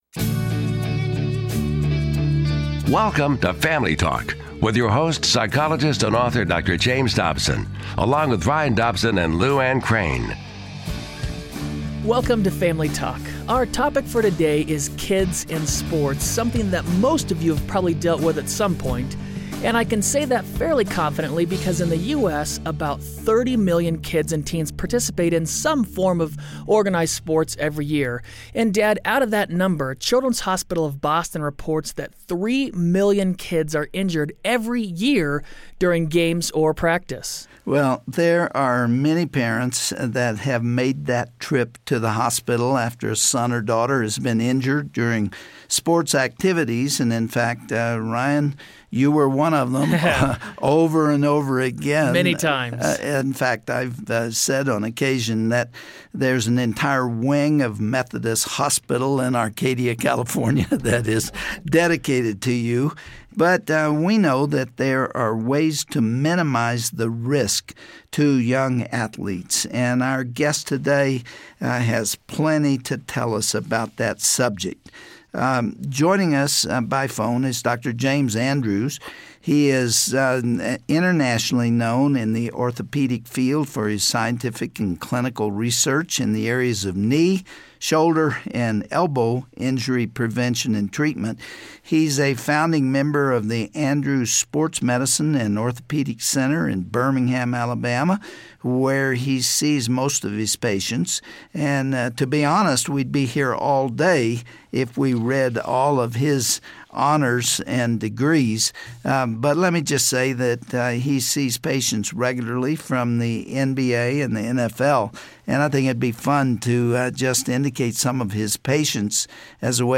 On today's program, Dr. Dobson talks with one of the premier orthopedic surgeons in the country. Listen as Dr. James Andrews reveals tips about keeping your child safe on the field or the court.